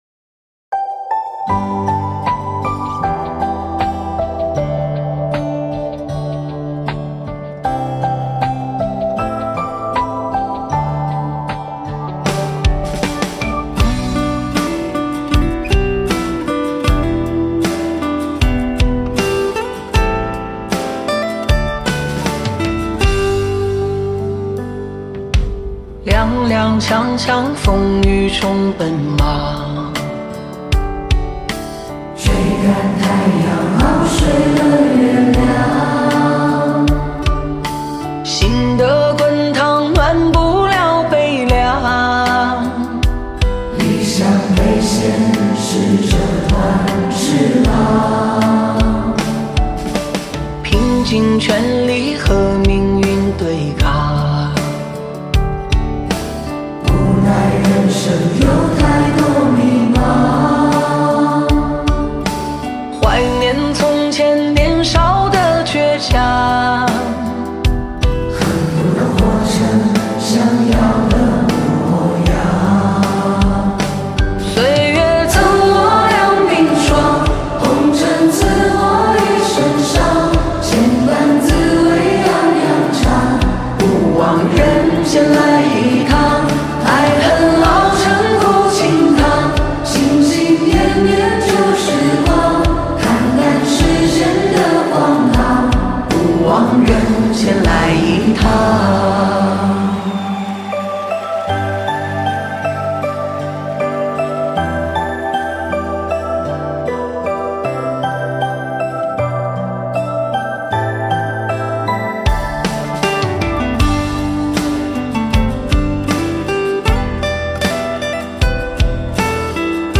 现场合唱